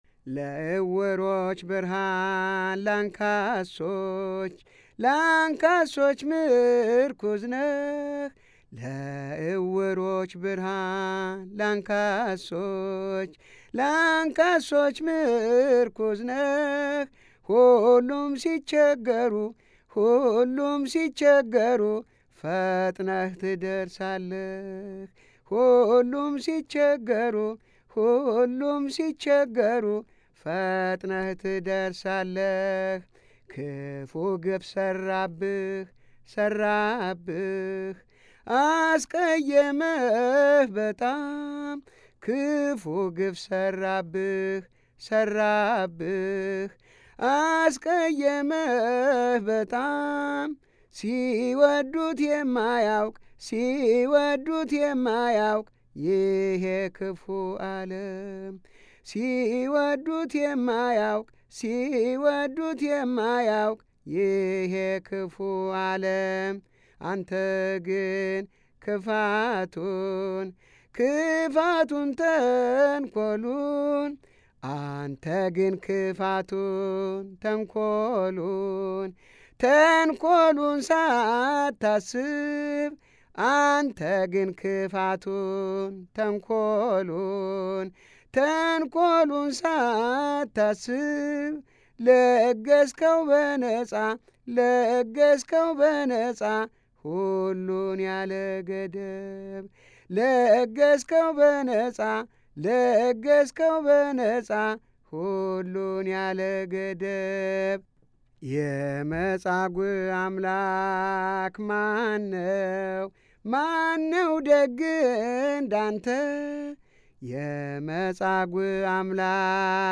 Dec 05 2020 የሚዘመሩ የህጻናትና ታዳጊዎች መዝሙራት